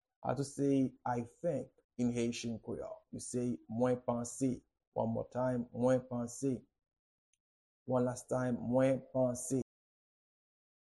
Pronunciation and Transcript:
How-to-say-I-think-in-Haitian-Creole-Mwen-panse-pronunciation.mp3